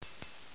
1 channel
ding.mp3